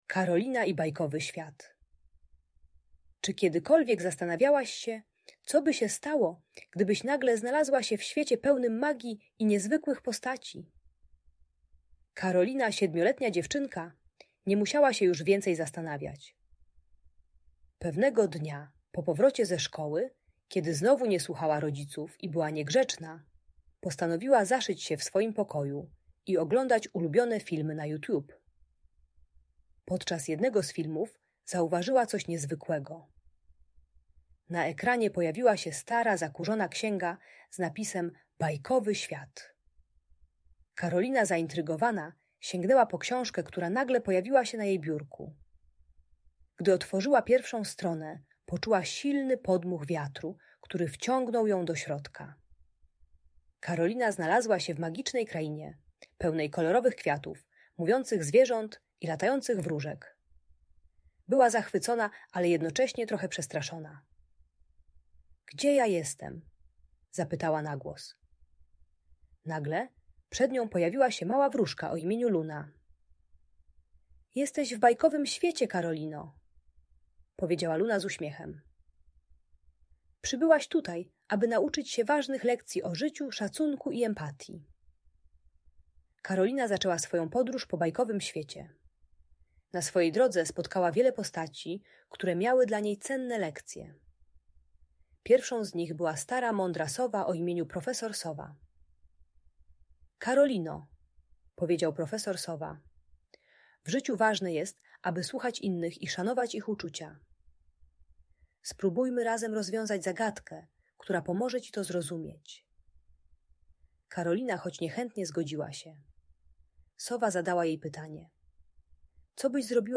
Karolina i Bajkowy Świat - Agresja do rodziców | Audiobajka